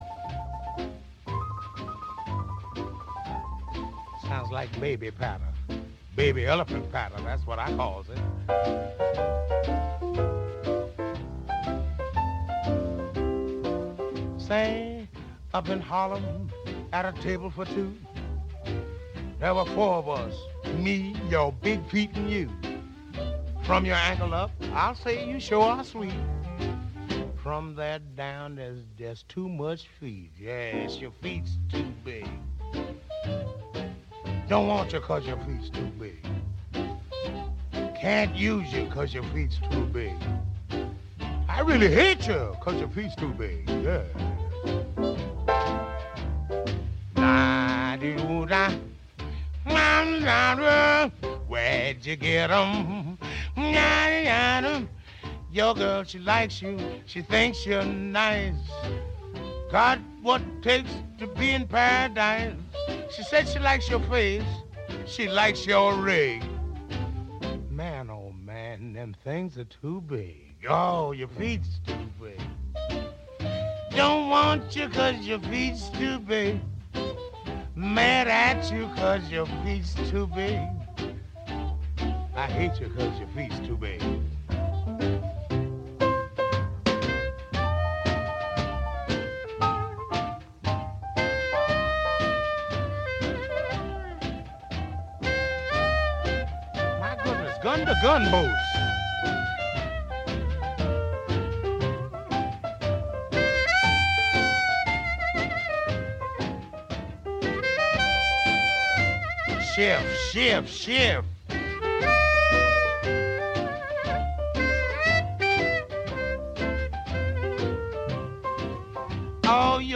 This week’s poetry reading is “Spring” by Madison Cawein courtesy of Librivox and Project Guetenberg.